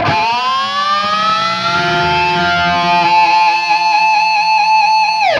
DIVEBOMB20-R.wav